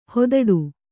♪ hodaḍu